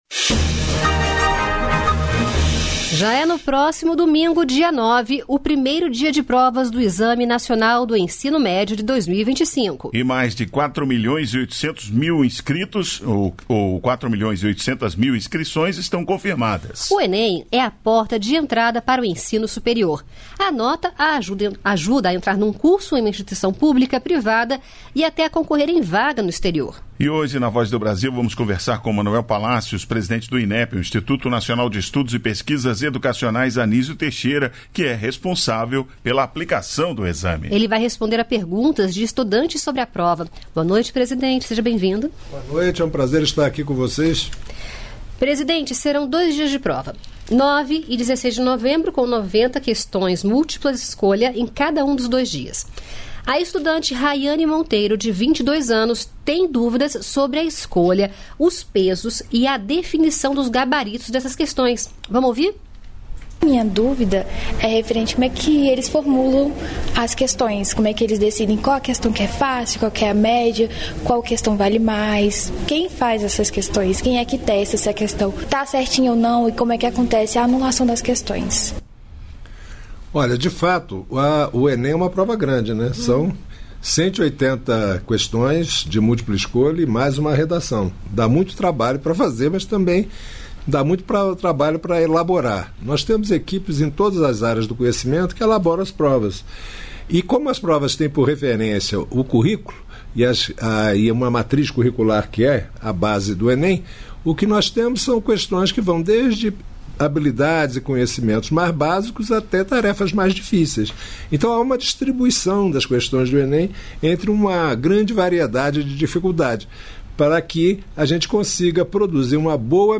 Entrevista com Adrualdo Catão, Secretário Nacional de Trânsito
Quase mil ações foram realizadas durante a Semana Nacional de Trânsito. O balanço das atividades foi tema da entrevista com o Secretário Nacional de Trânsito nesta segunda-feira (30).